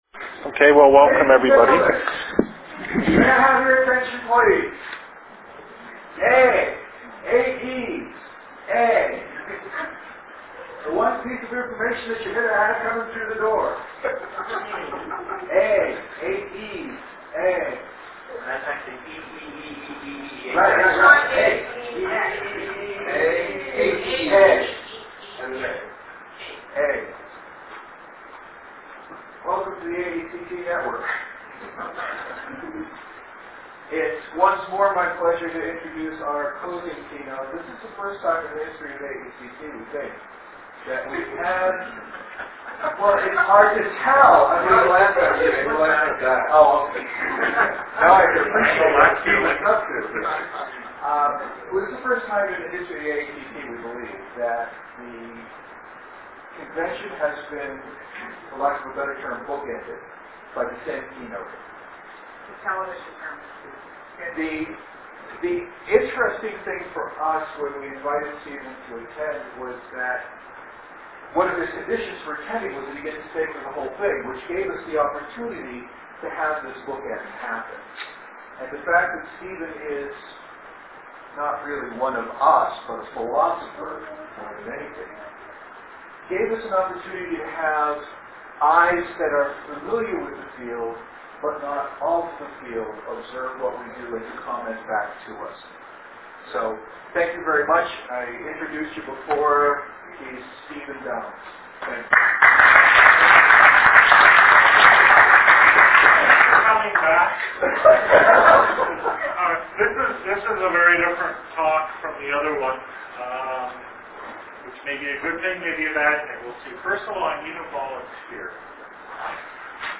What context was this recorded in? Closing keynote for AECT in Anaheim. The purpose of this talk was to reflect on the various presentations and events at the conference and think about the major issues raised. I chose to contrast the traditional academic paper with web-enabled forms of communication like the email newsletter.